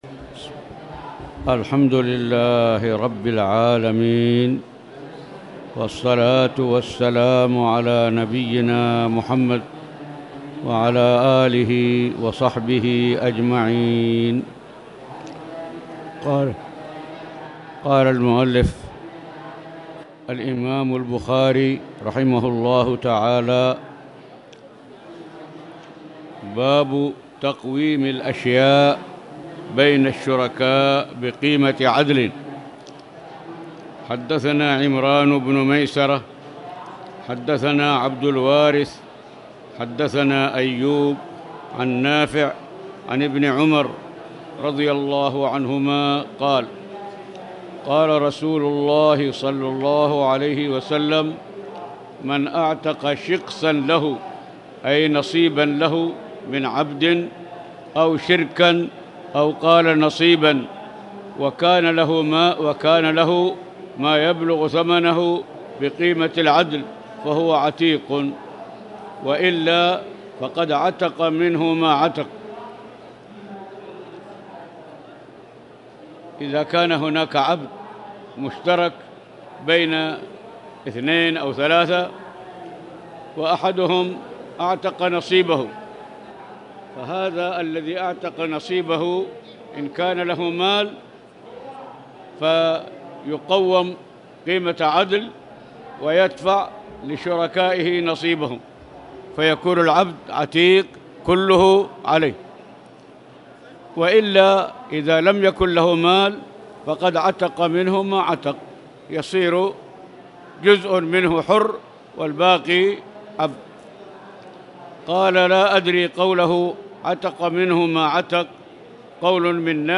تاريخ النشر ١٥ رجب ١٤٣٨ هـ المكان: المسجد الحرام الشيخ